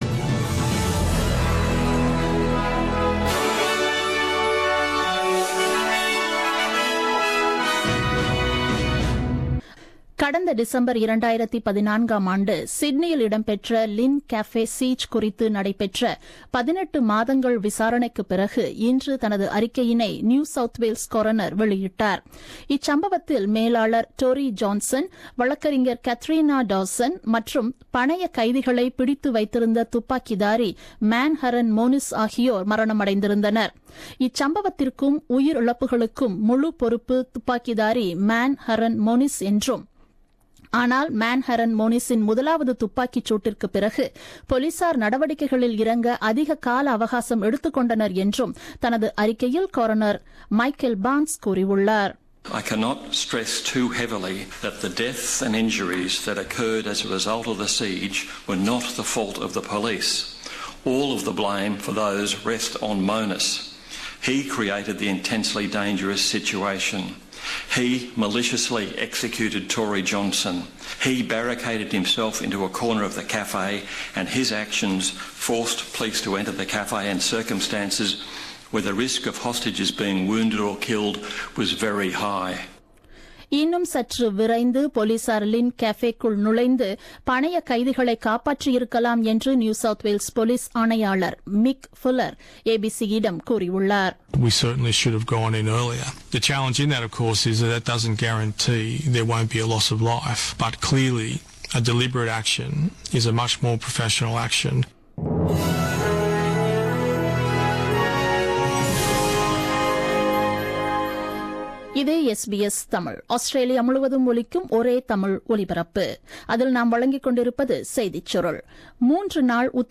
The news bulletin broadcasted on 24 May 2017 at 8pm.